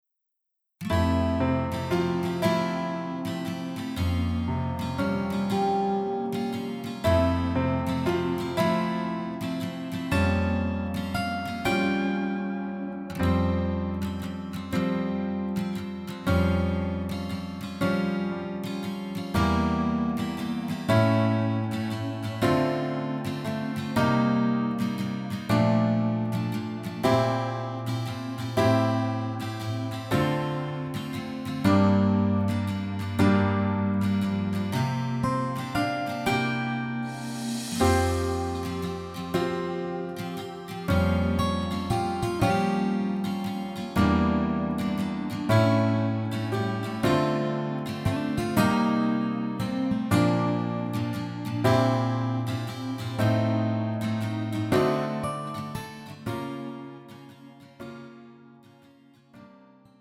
음정 -1키 2:50
장르 가요 구분 Pro MR